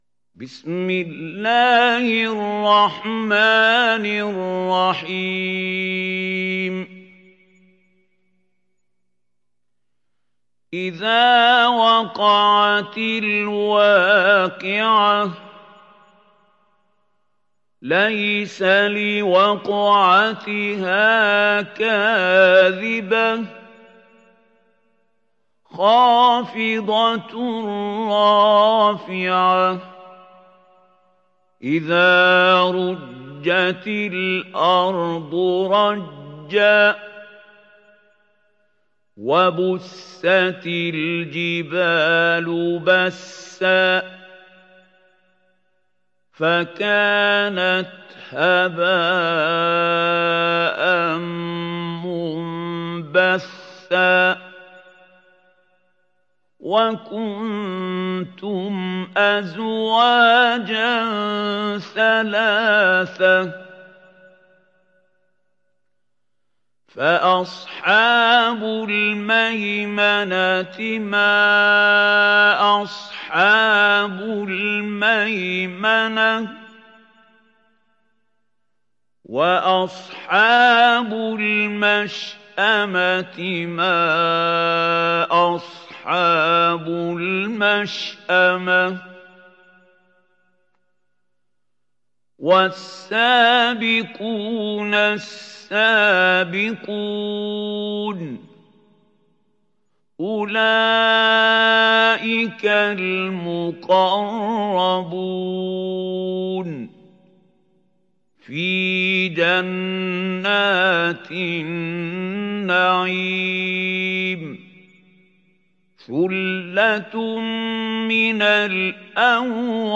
Surat Al Waqiah Download mp3 Mahmoud Khalil Al Hussary Riwayat Hafs dari Asim, Download Quran dan mendengarkan mp3 tautan langsung penuh